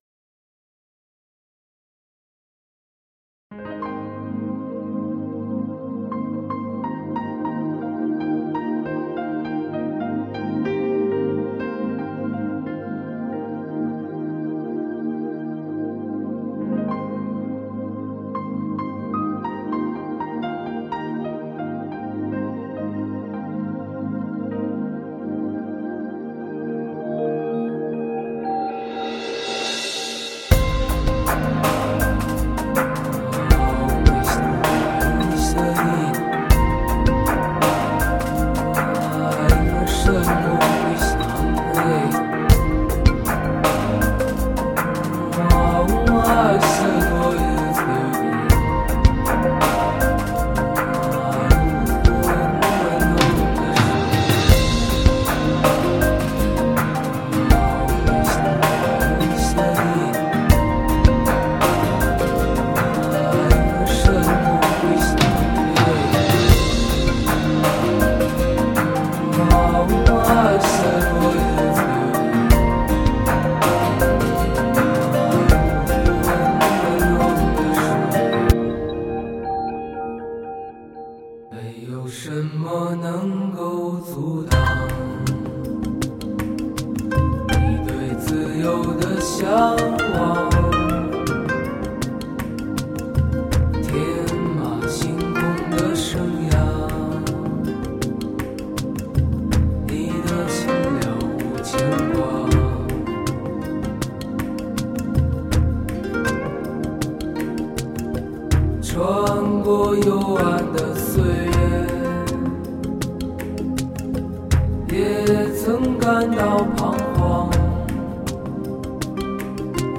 HI-FI顶级人声测试天碟